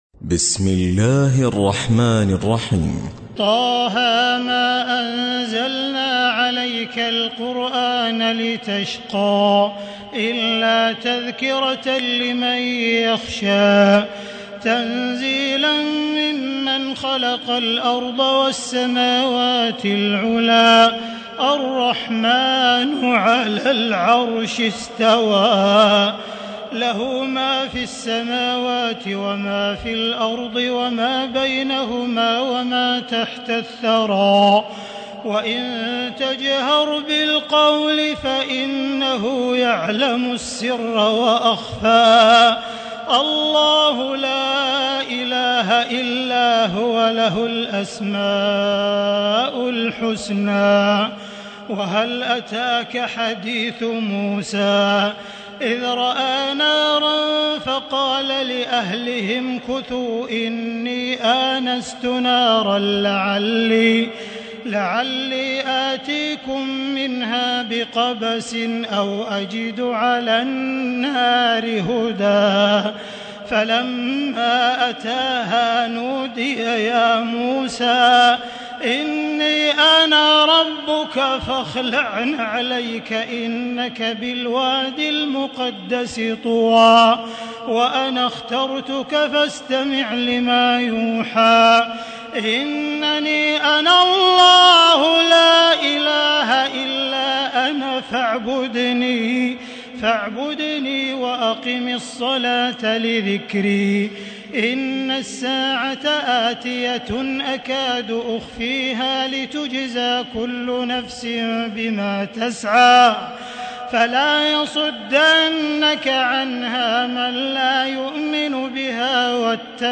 تراويح الليلة الخامسة عشر رمضان 1437هـ سورة طه كاملة Taraweeh 15 st night Ramadan 1437H from Surah Taa-Haa > تراويح الحرم المكي عام 1437 🕋 > التراويح - تلاوات الحرمين